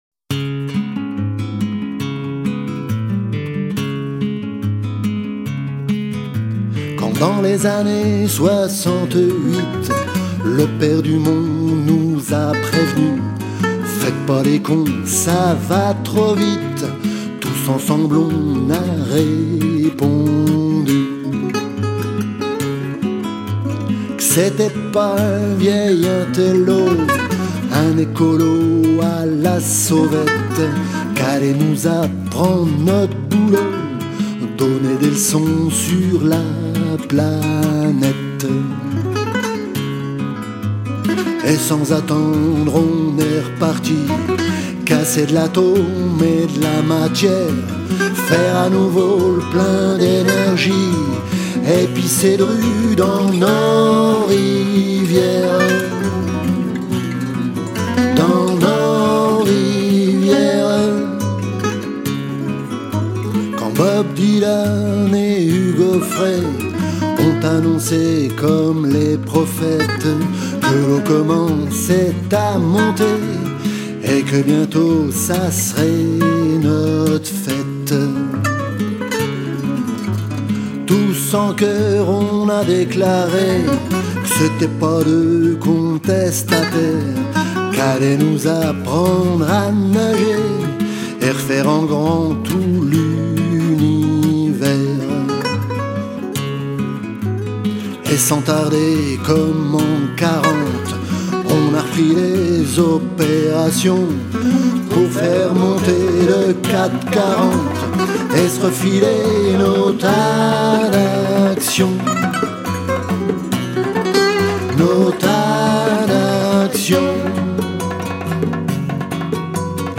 chante 13 titres originaux